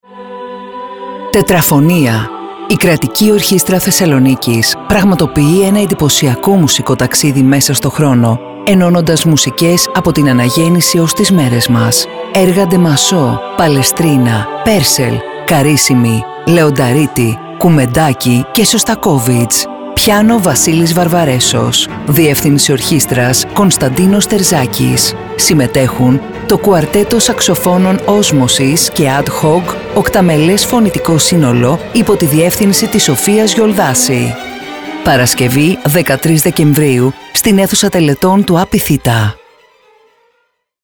Ραδιοφωνικό σποτ 13δεκ24_Τετραφωνία.mp3